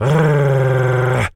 pgs/Assets/Audio/Animal_Impersonations/wolf_growl_05.wav at master
wolf_growl_05.wav